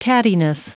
Prononciation du mot tattiness en anglais (fichier audio)
Prononciation du mot : tattiness